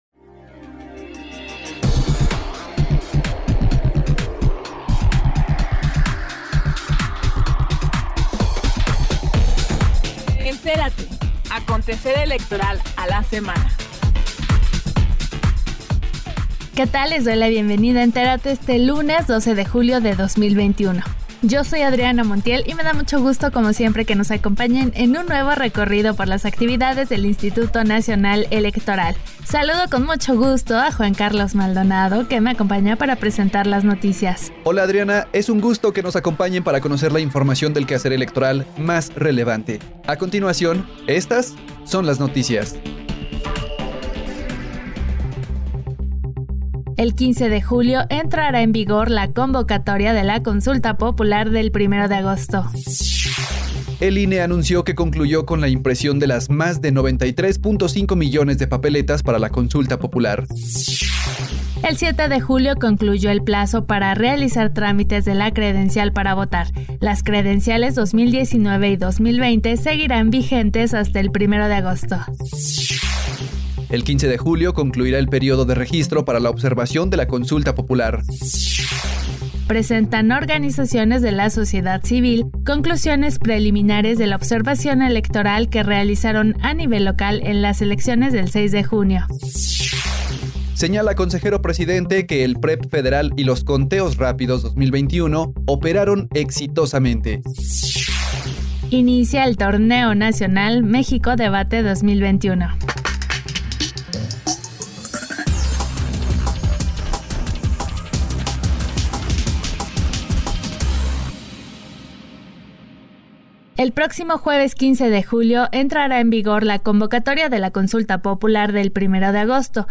NOTICIARIO 12 JULIO 2021